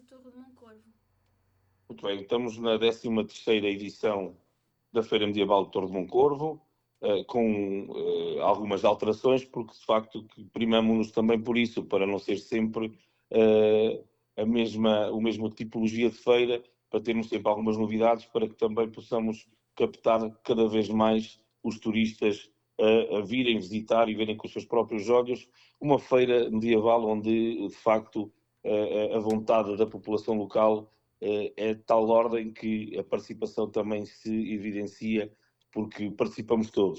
Segundo o presidente da Câmara Municipal de Torre de Moncorvo, José Meneses, a edição deste ano apresenta novidades e reforça o envolvimento da população local: